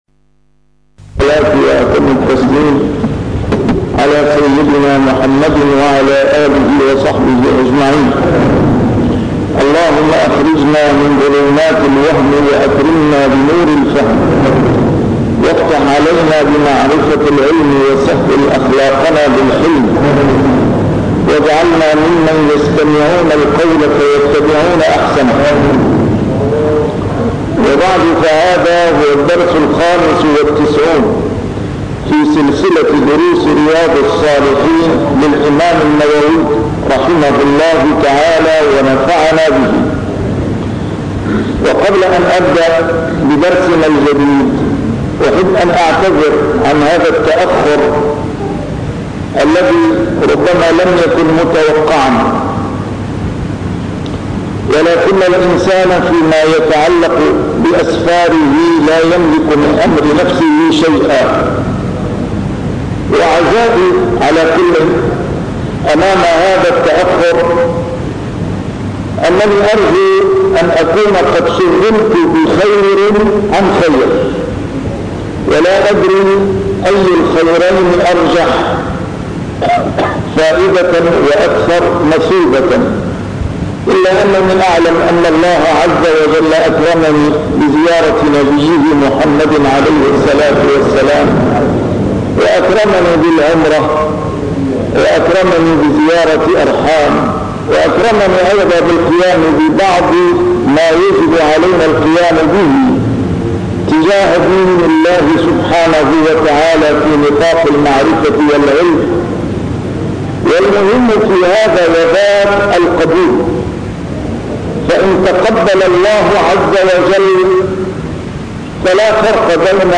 A MARTYR SCHOLAR: IMAM MUHAMMAD SAEED RAMADAN AL-BOUTI - الدروس العلمية - شرح كتاب رياض الصالحين - 95- شرح رياض الصالحين: المبادرة إلى الخيرات